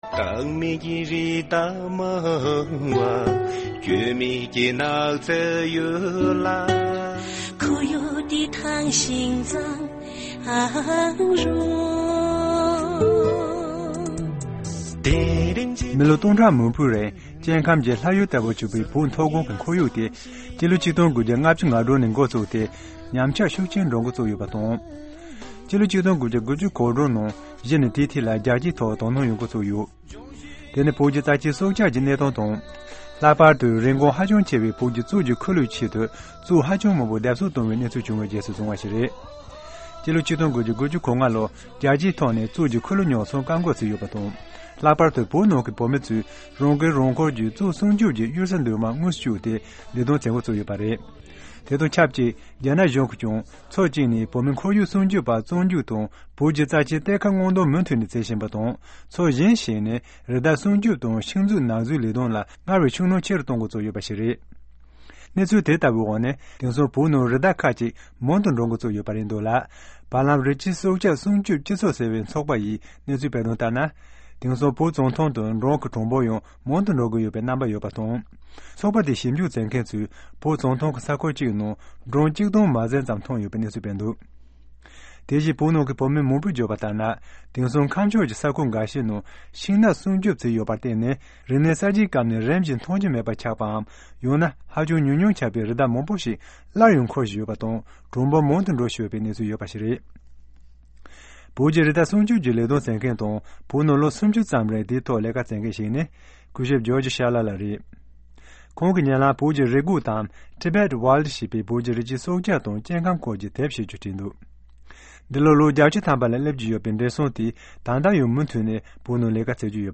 གནས་འདྲི་ཞུས་པ་ཞིག་སྙན་སྒྲོན་ཞུ་གི་ཡིན།